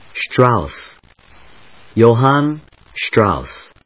音節Strauss 発音記号・読み方/ʃtrάʊs, strάʊs/, Johann /joʊhάːnjəʊhˈæn/発音を聞く